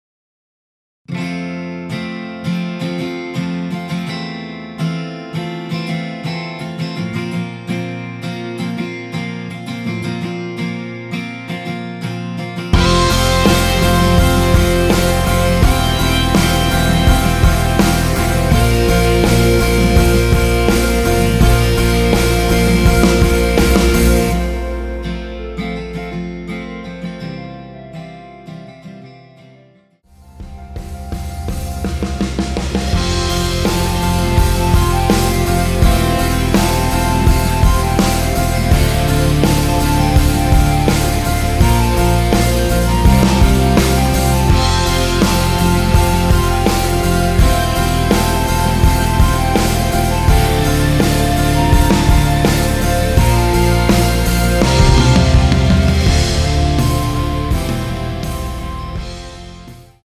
원키에서(+5)올린 멜로디 포함된 MR입니다.(미리듣기 확인)
앞부분30초, 뒷부분30초씩 편집해서 올려 드리고 있습니다.
중간에 음이 끈어지고 다시 나오는 이유는